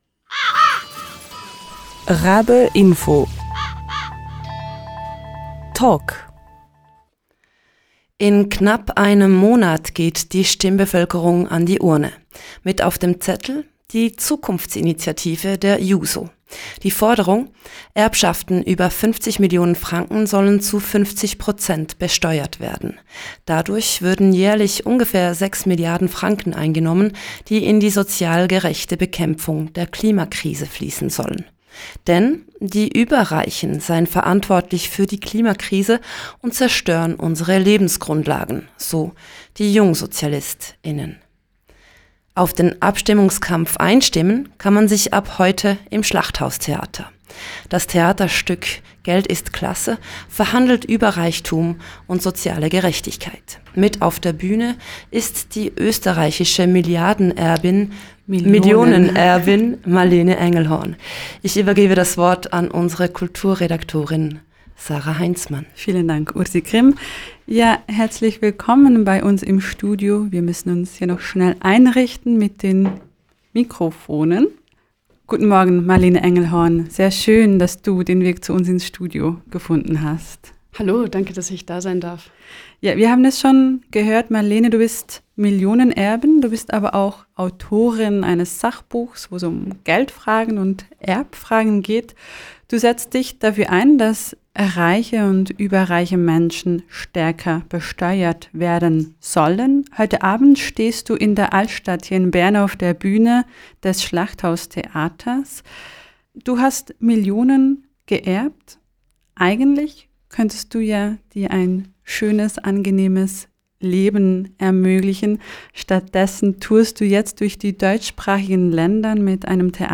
RaBe: Marlene Engelhorn, du bist Millionenerbin, Autorin und Aktivistin.